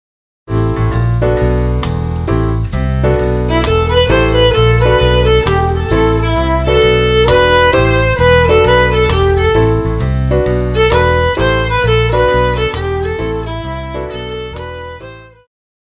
Jazz,Pop
Viola
Band
Instrumental
Smooth Jazz,Rock
Only backing